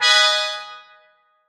found_noise.wav